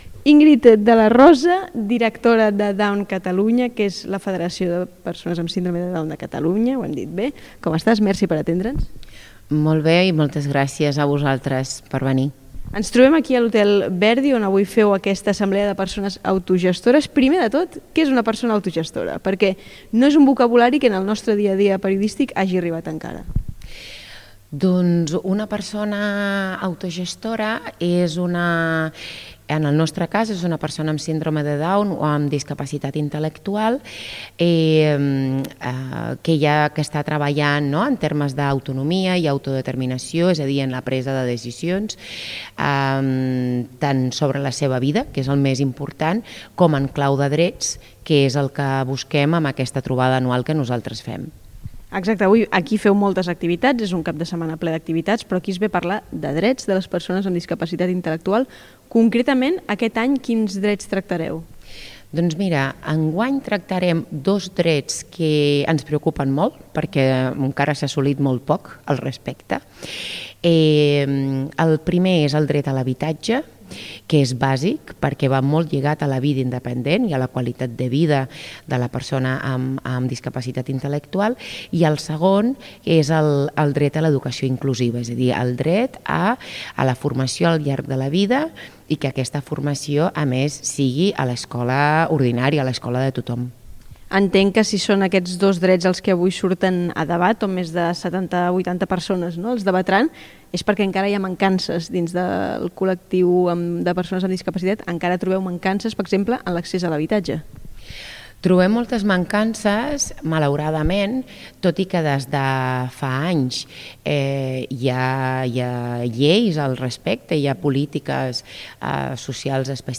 ENTREVISTA DOWN CATALUNYA.mp3